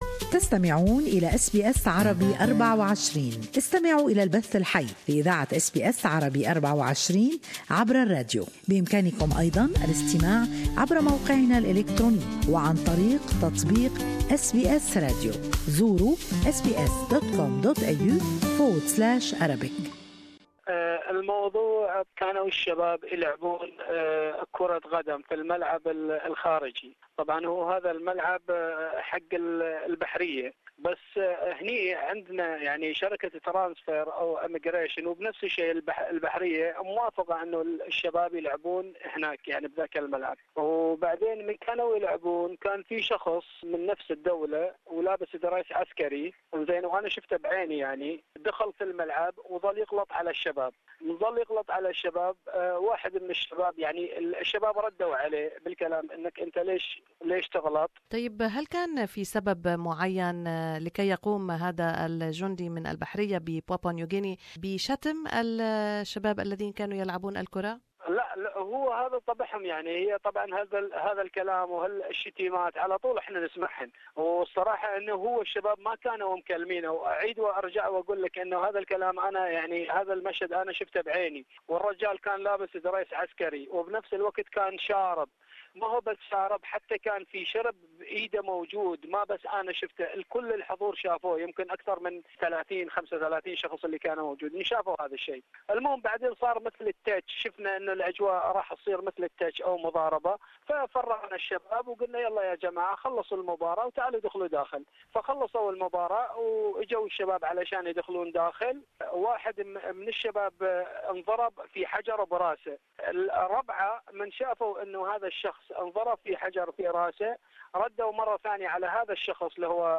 Eyewitness account of the latest violence in Manus detention centre